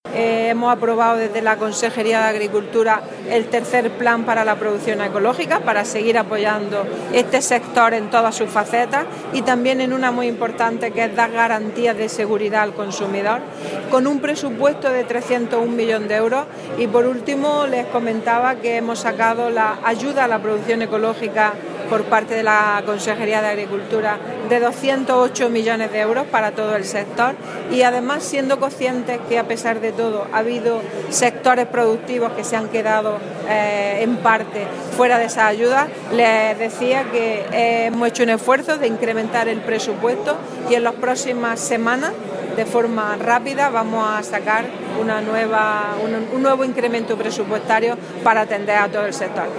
Carmen Ortiz ha pronunciado estas palabras en Sevilla durante la clausura de la cuarta edición de la Conferencia Europea sobre elaboración de productos ecológicos, organizada por primera vez en España por la asociación europea de la Federación Internacional de la Agricultura Ecológica (Ifoam-UE) y la Asociación Valor Ecológico-Ecovalia.
Declaraciones consejera sobre plan ecológico